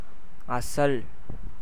pronunciation; transl.